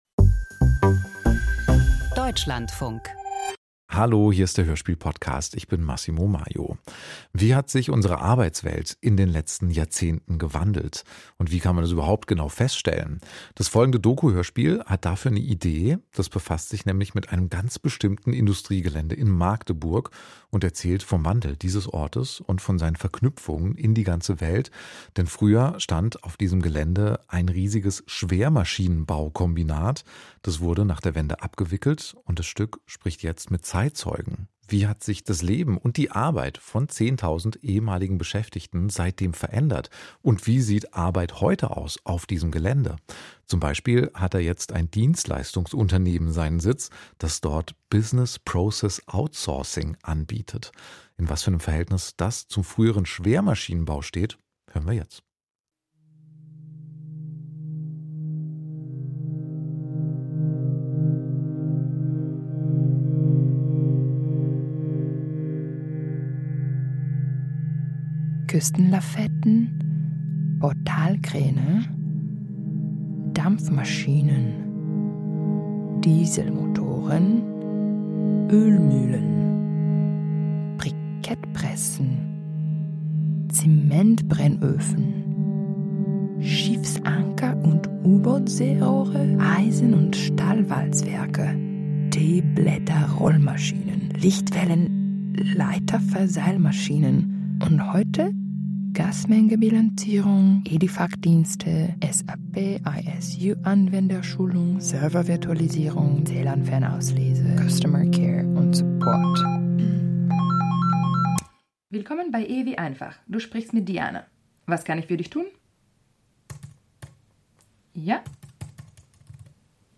• Doku-Hörspiel • Seit dem 19.